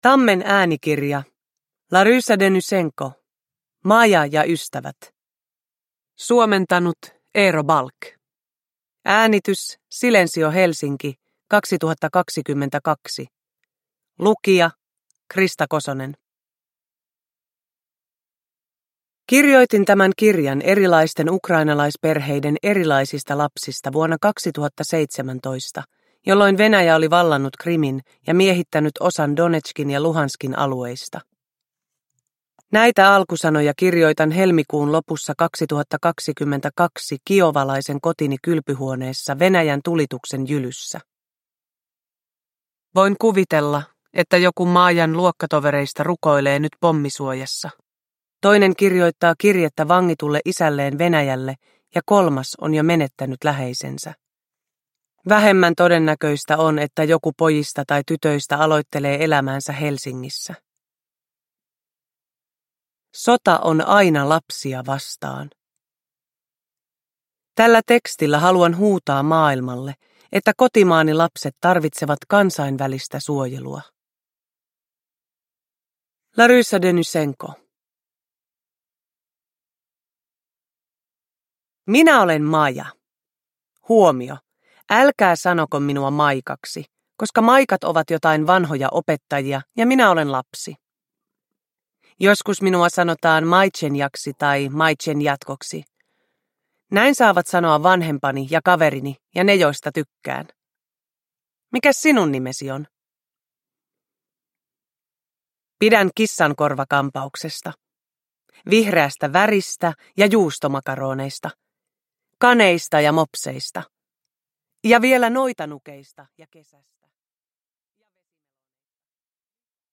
Maja ja ystävät – Ljudbok – Laddas ner
Uppläsare: Krista Kosonen